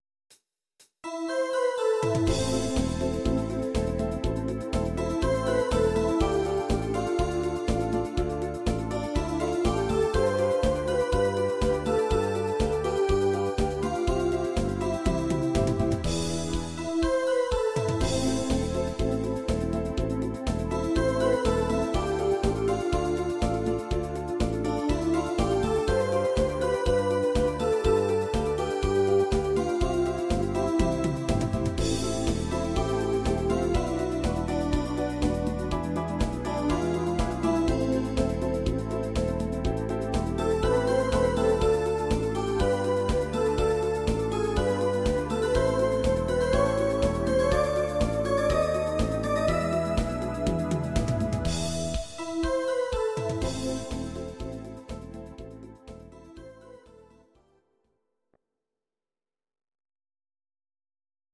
These are MP3 versions of our MIDI file catalogue.
Please note: no vocals and no karaoke included.
Your-Mix: Disco (726)
instr. Synthesizer